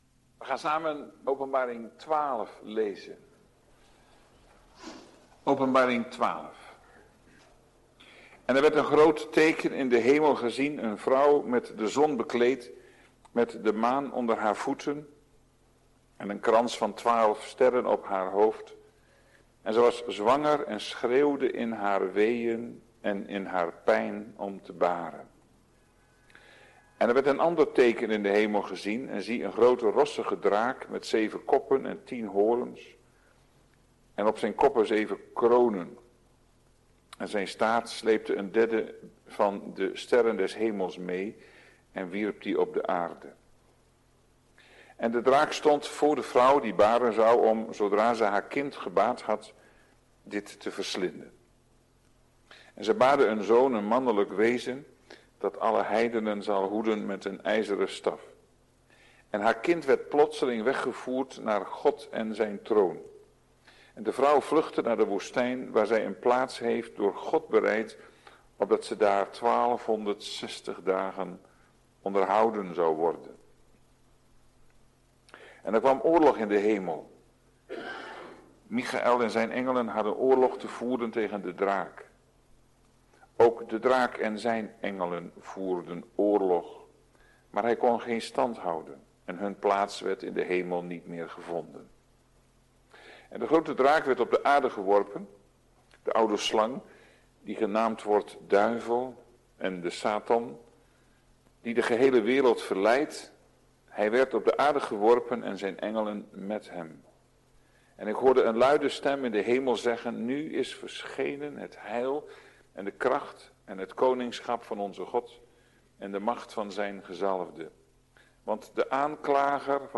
Na ongeveer 45 minuten (wanneer kant A overgaat naar kant B) kan een korte stilte voorkomen.